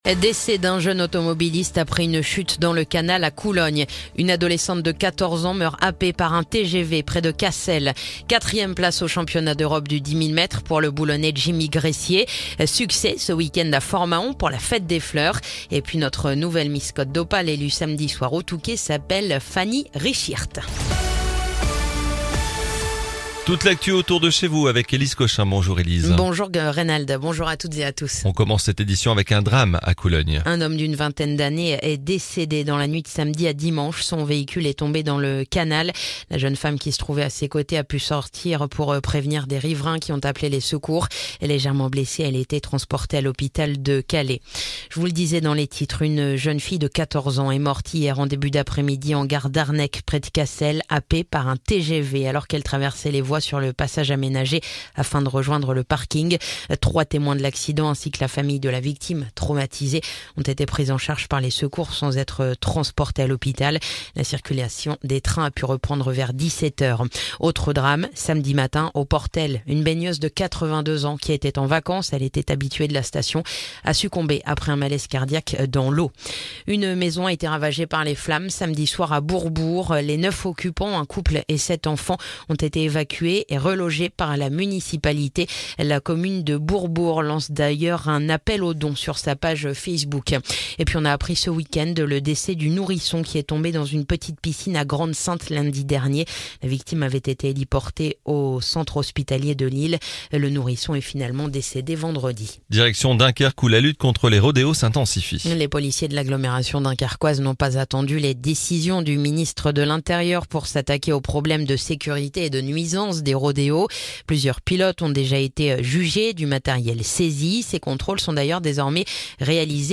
Le journal du lundi 22 août